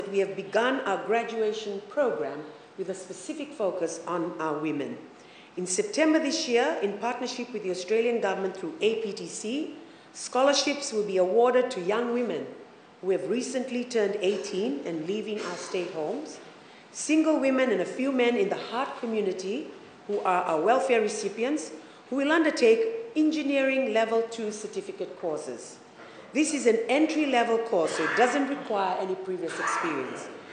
Speaking in Parliament last night, Tabuya says clients should not be on the program for many years.